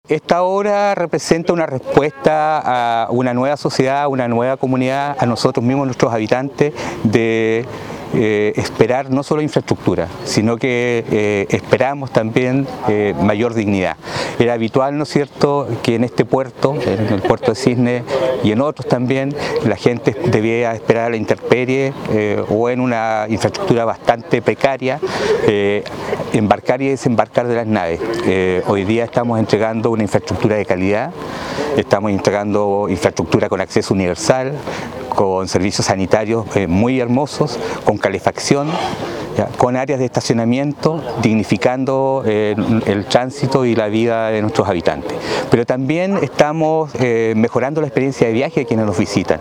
“Esta obra es una respuesta a una nueva sociedad, que también espera dignidad y se la estamos entregando con una infraestructura con acceso universal, con calefacción, mejorando la experiencia de viaje”, señaló el seremi de Obras Públicas, Patricio Sanhueza.
Audio-cuna-1-Seremi-MOP.mp3